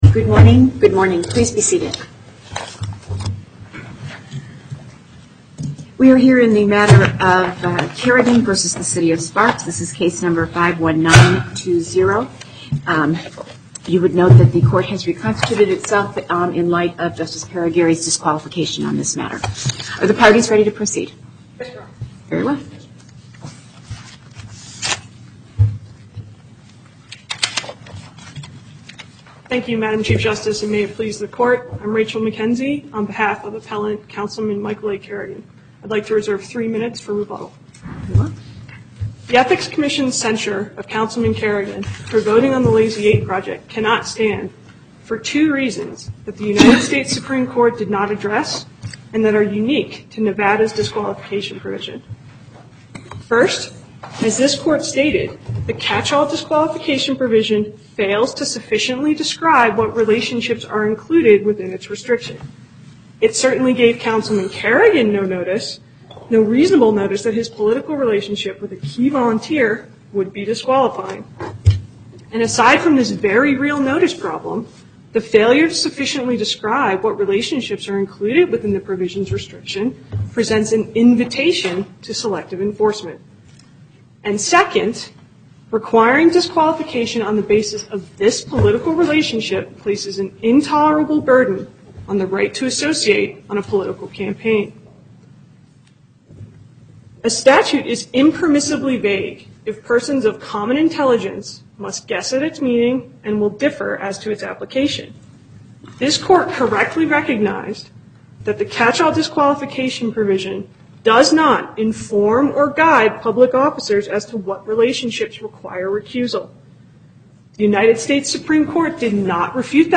Location: Carson City En Banc Court, Chief Justice Saitta Presiding